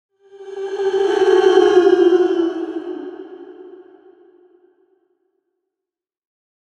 Ghost Crying Sound Effect
A supernatural wailing sound adds a ghostly and terrifying touch to films, games, and scary scenes. Create an eerie atmosphere and enhance horror projects with this haunting audio.
Ghost-crying-sound-effect.mp3